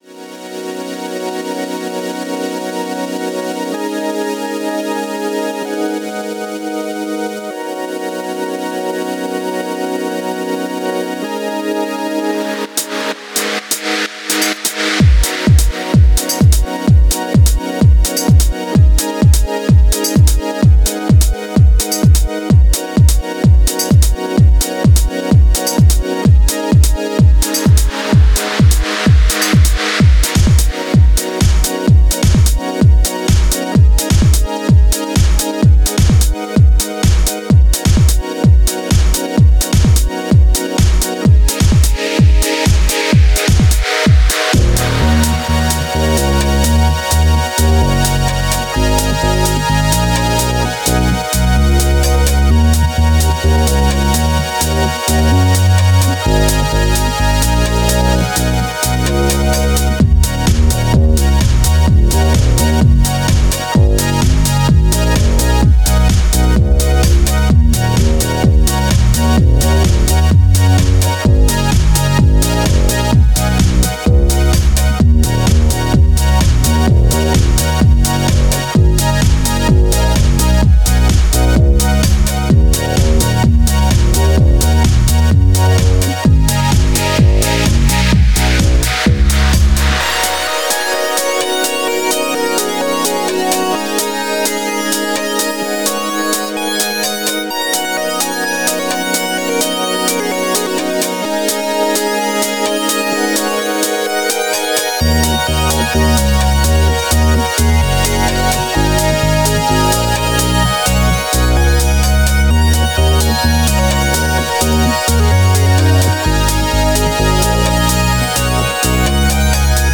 Here's a chill melodic house track for to enjoy on those summer nights ^^ Hopefully It warms up those in the depths of winter too X ...
Music / House